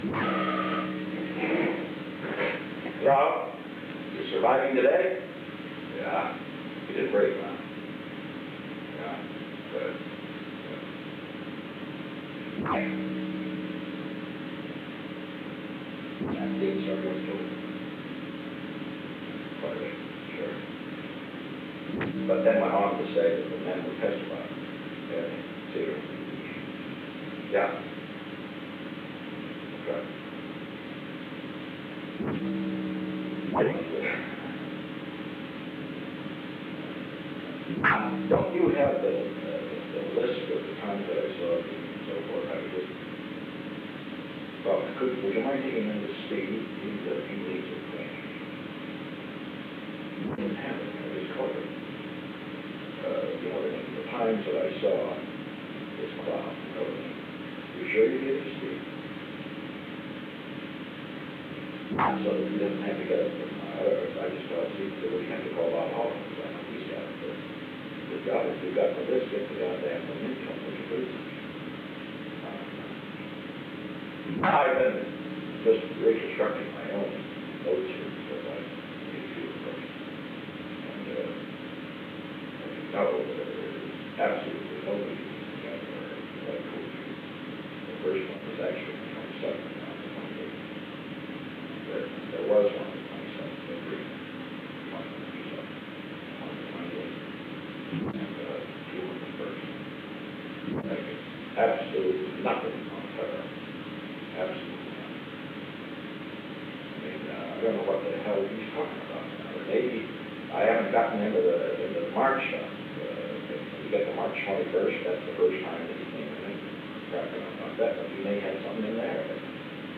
Secret White House Tapes
Conversation No. 442-21
Location: Executive Office Building
The President talked with Ronald L. Ziegler.